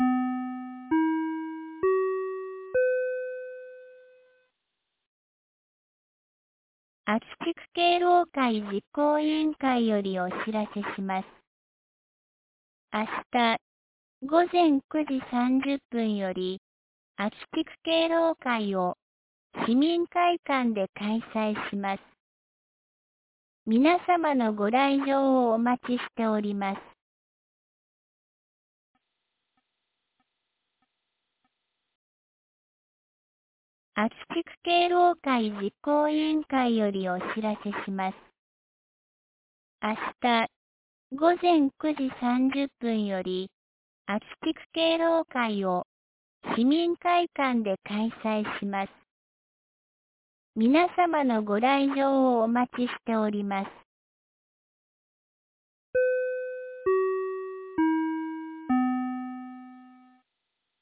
2024年09月16日 10時01分に、安芸市より安芸へ放送がありました。